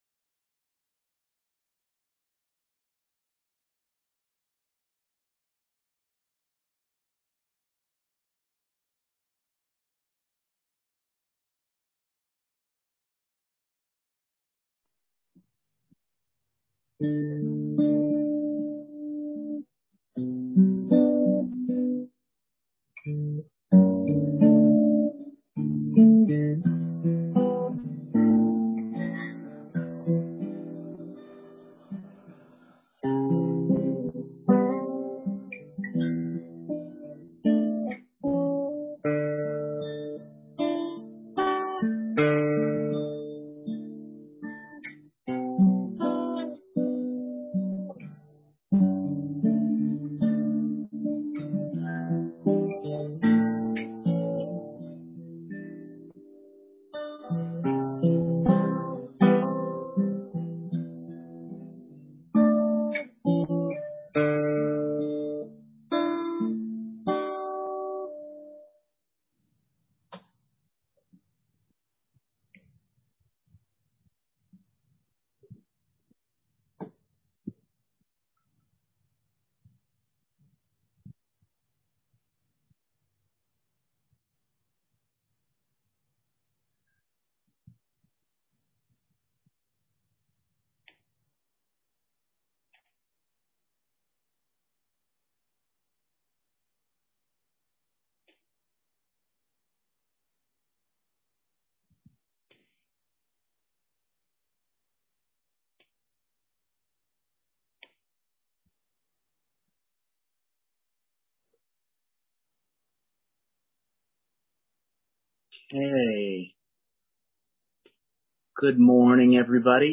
Sermon:A Wand, a Cloak, a Stone... and the Armor of God - St. Matthews United Methodist Church